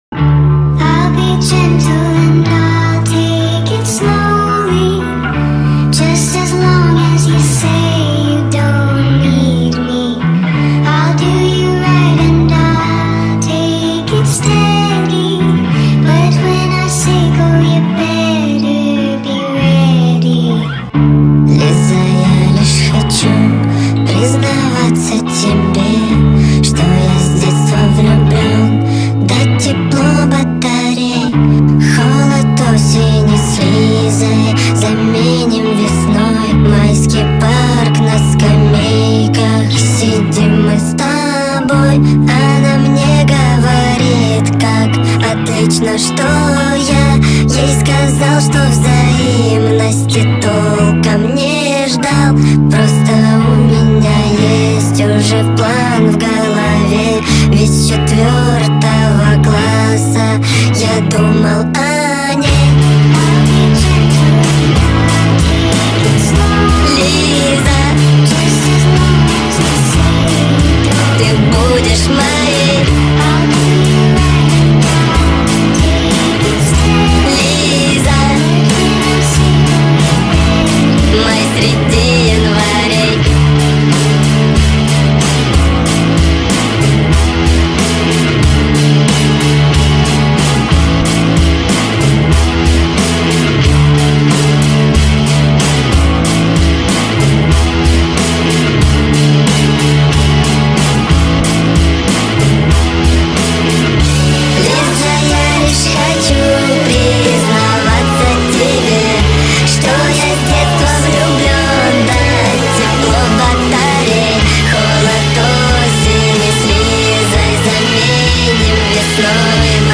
Sped Up TikTok Remix